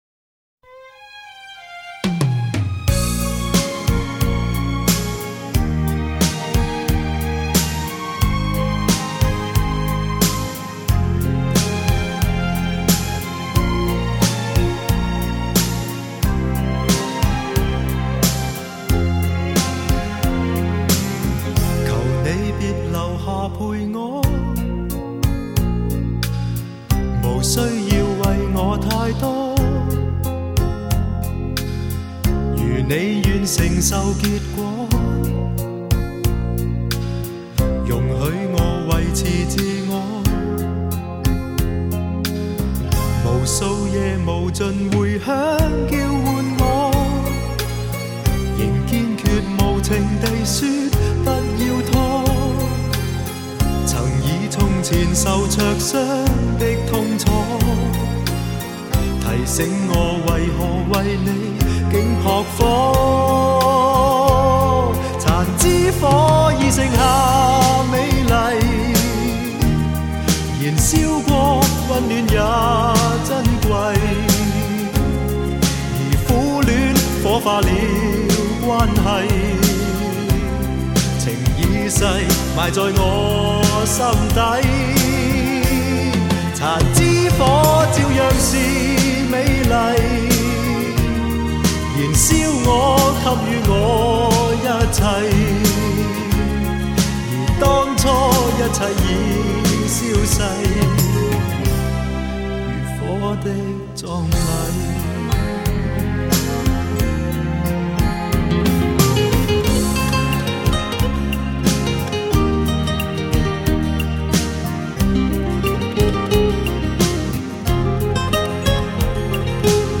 中国香港流行乐男歌手
雄伟典范乐曲 必唯天作之合HI-FI典范 极致人声
高密度34bit数码录音